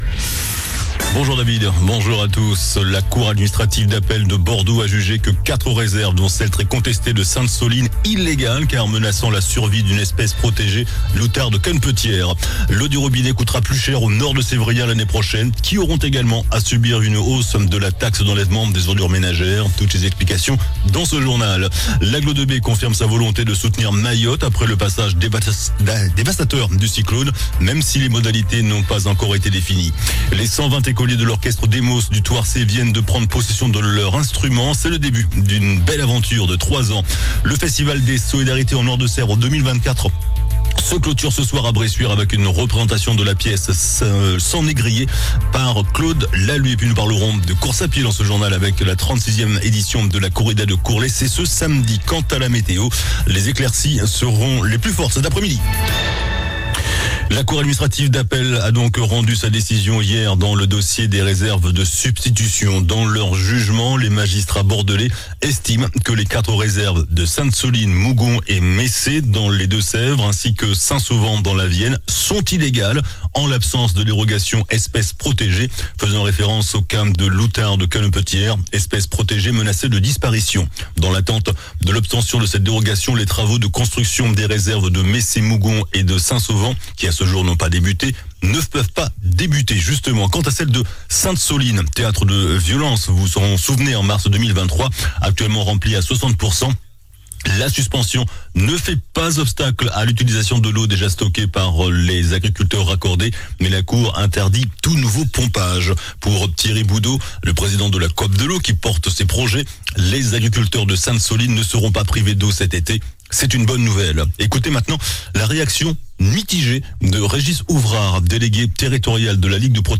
JOURNAL DU JEUDI 19 DECEMBRE ( MIDI )